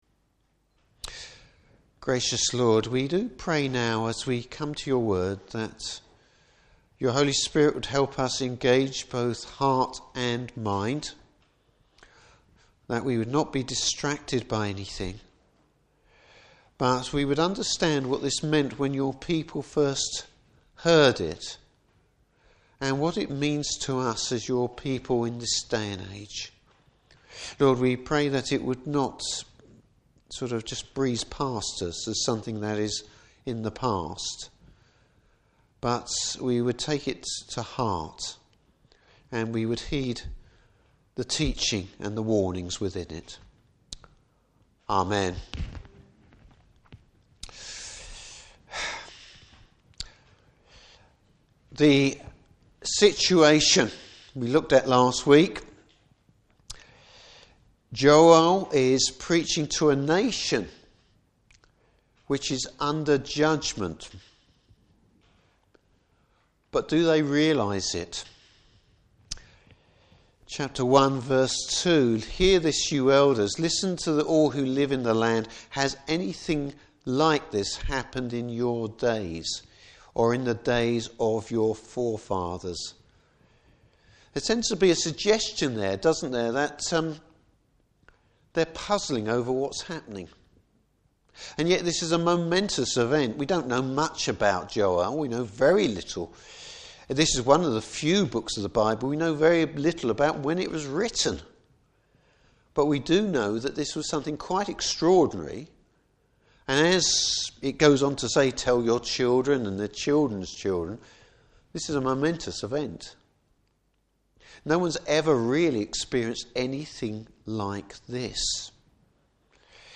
Service Type: Evening Service How a bit of self examination can be helpful!